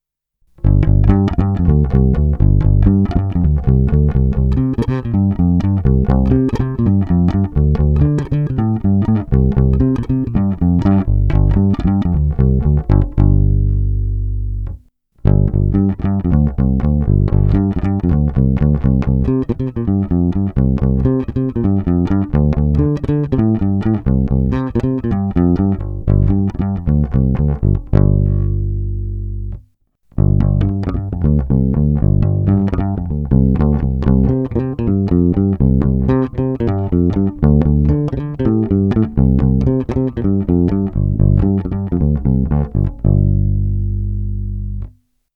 Nový snímač je víc vintage, zpět do šedesátkových let.
Nahrávka přes Darkglass Alpha Omega Ultra se zapnutou simulací aparátu a také přes kompresor TC Electronic SpectraComp prsty, se zkreslením a nakonec slapem.